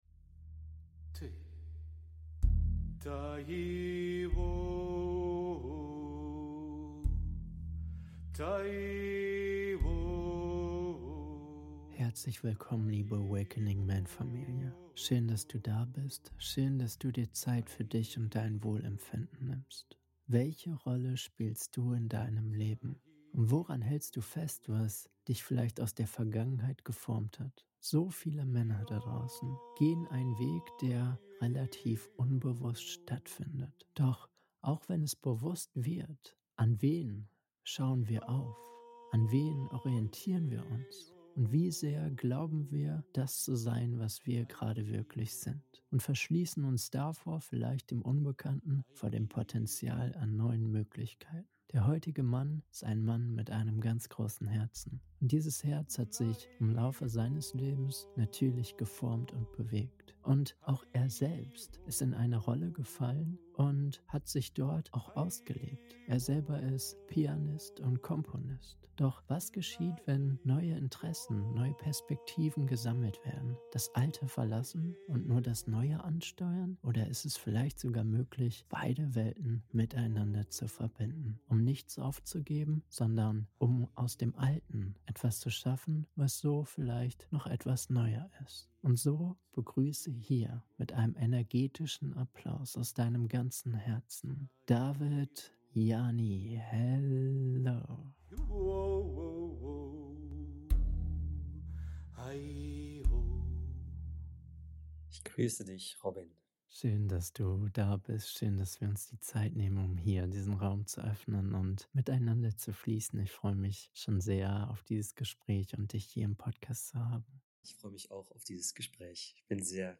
Yogakonzert als Brücke zur Göttlichkeit - Interview